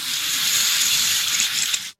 Rope Sliding
A rope sliding through hands or a pulley with friction burn and fiber hiss
rope-sliding.mp3